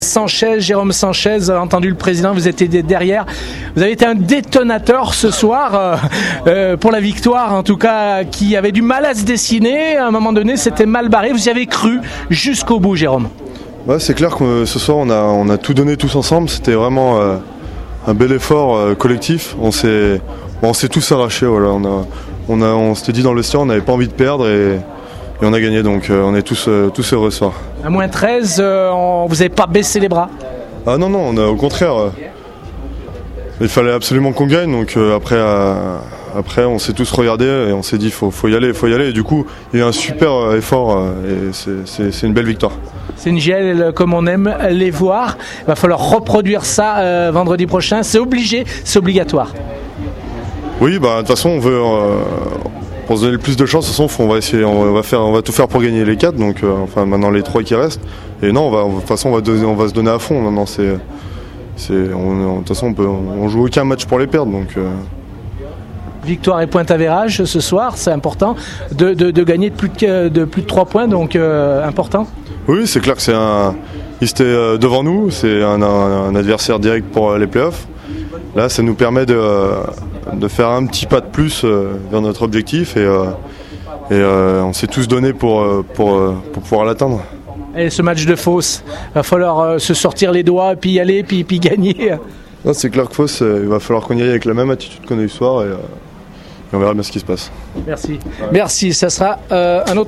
réactions d’après-match